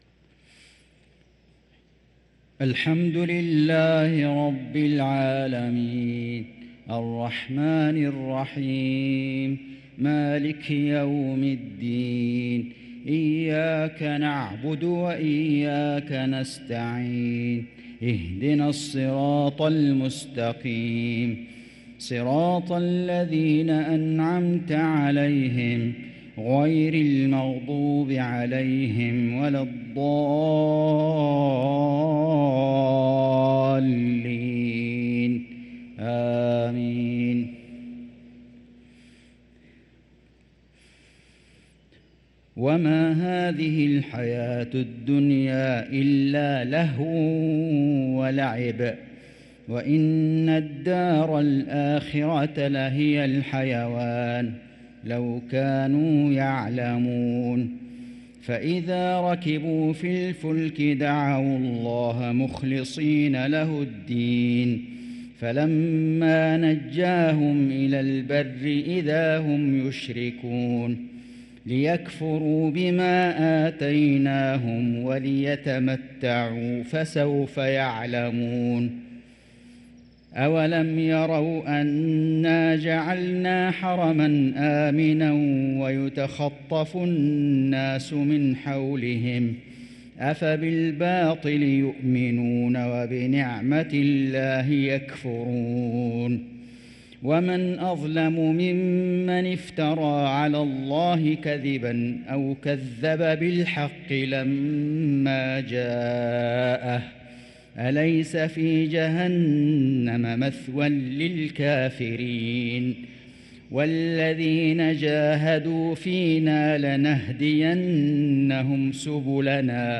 صلاة المغرب للقارئ فيصل غزاوي 28 ربيع الأول 1445 هـ